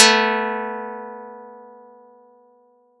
Audacity_pluck_2_13.wav